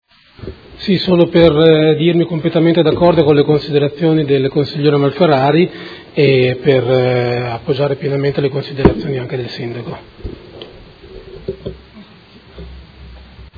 Seduta del 10/03/2016. Conclude dibattito su interrogazione del Gruppo Consiliare Movimento 5 Stelle avente per oggetto: Cooperativa La Carpigiana Service Primo firmatario Consigliere Fantoni